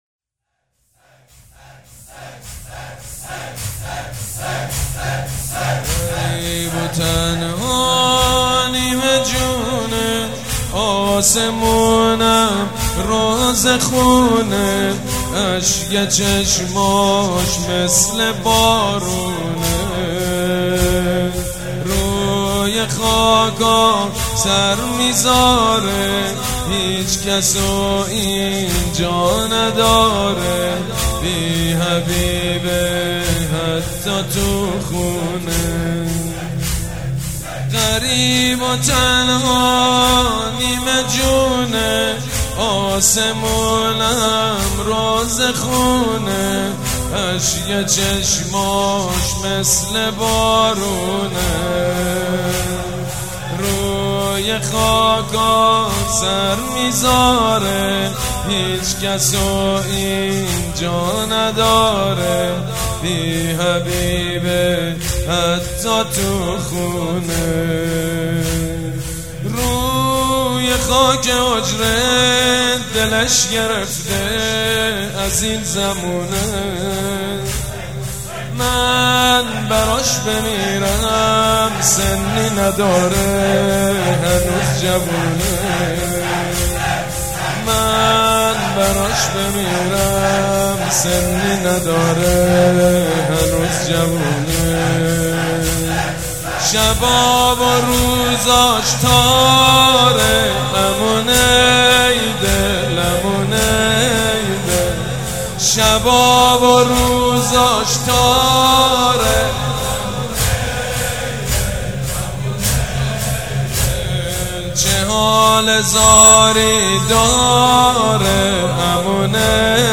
نوحه جانسوز شهادت حضرت جوادالائمه علیه السلام با صدای حاج سیدمجید بنی فاطمه که شب گذشته در هیات ریحانه الحسین علیه السلام برگزار گردید.